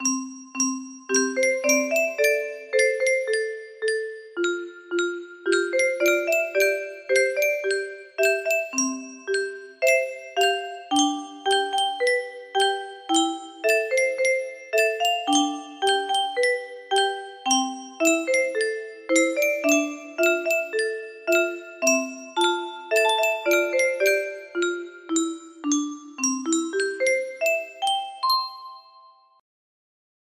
summer music box melody